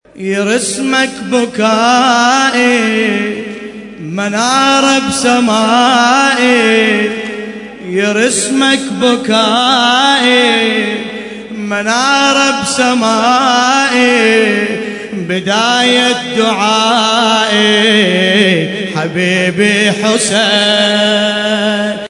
بدون لطم